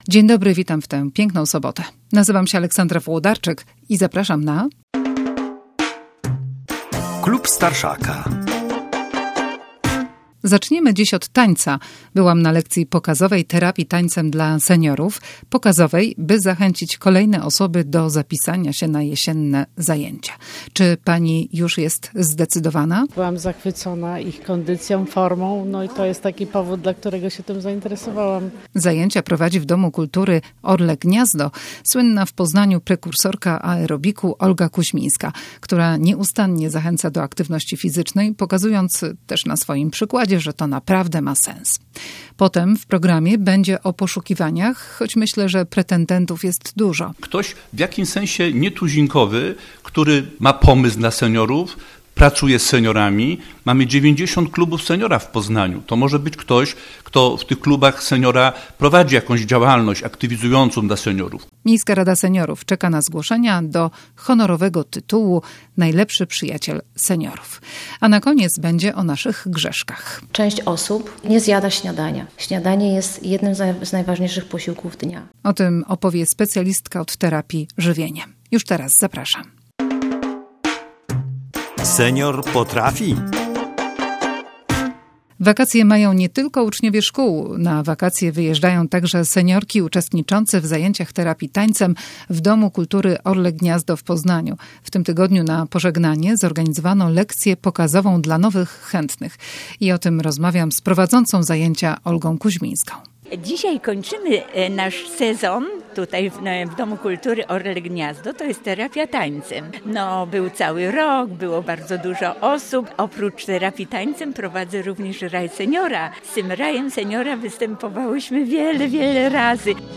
A seniorki opowiedzą, dlaczego warto. Potem o ogłoszonym konkursie na Najlepszego Przyjaciela Seniora w Poznaniu.
Na koniec o grzechach żywieniowych seniorów i o tym, jak prawidłowo się odżywiać opowie terapeutka żywieniowa.